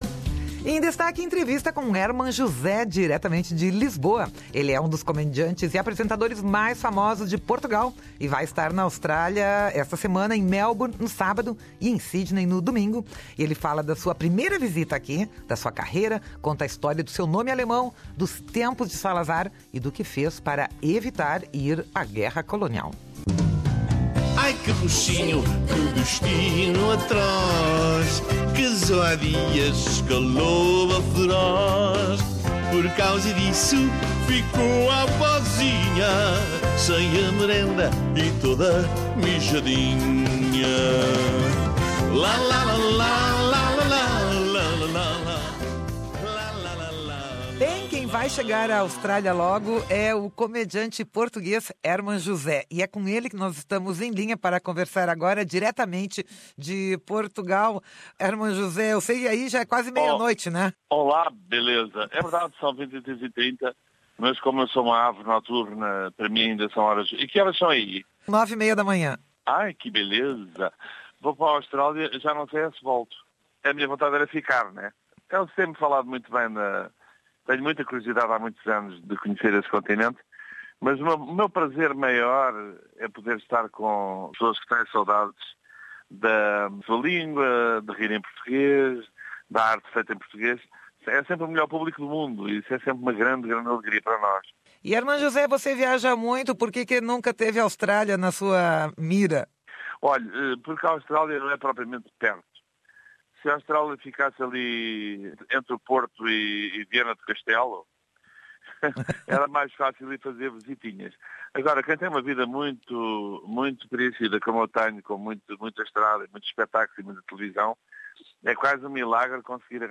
Conversamos com Herman José, um dos comediantes e apresentadores mais famosos de Portugal, por telefone, diretamente de Lisboa.